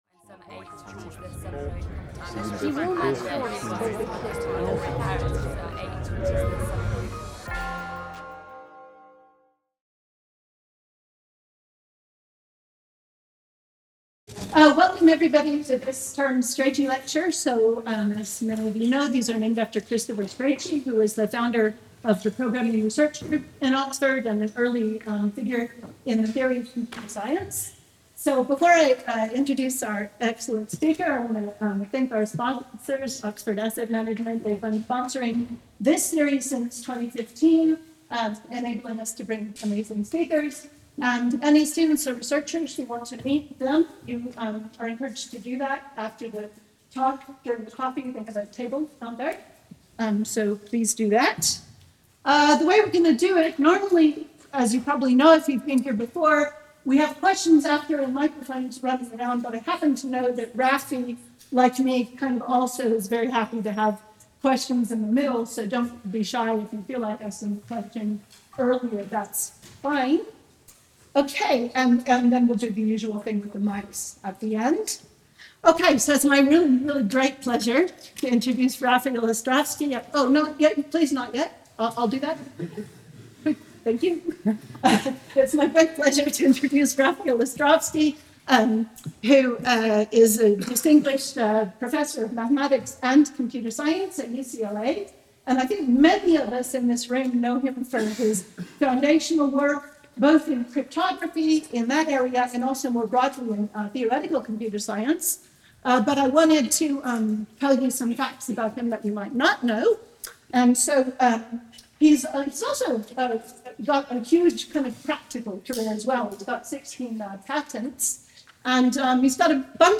This series covers the Strachey Lectures, a series of termly computer science lectures named after Christopher Strachey, the first Professor of Computation at the University of Oxford.